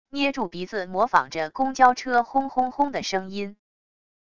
捏住鼻子模仿着公交车轰轰轰的声音wav音频